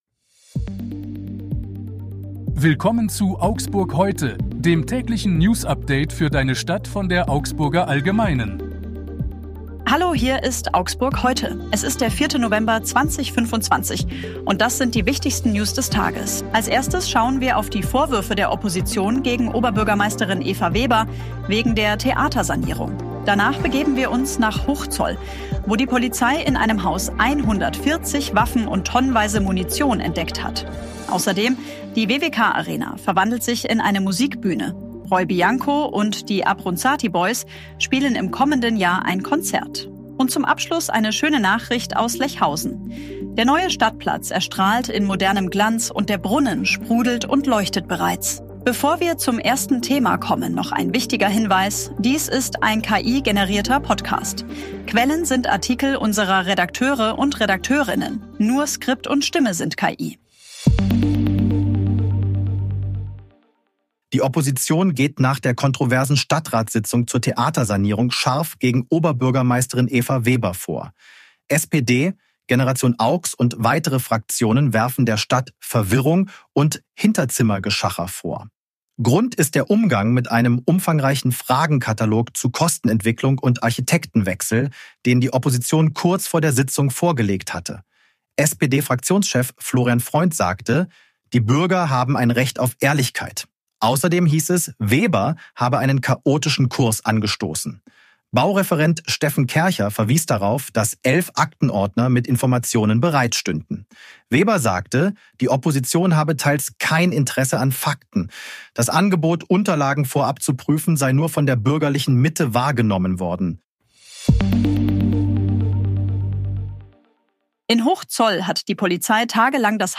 Stimme sind KI.